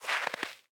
Minecraft Version Minecraft Version snapshot Latest Release | Latest Snapshot snapshot / assets / minecraft / sounds / block / powder_snow / step5.ogg Compare With Compare With Latest Release | Latest Snapshot
step5.ogg